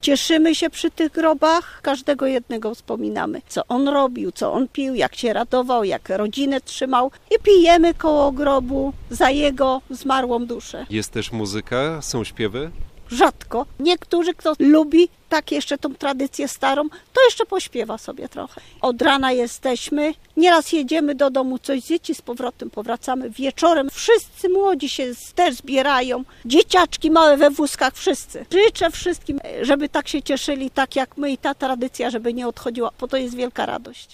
- opowiada kobieta porządkująca grobowiec swoich bliskich.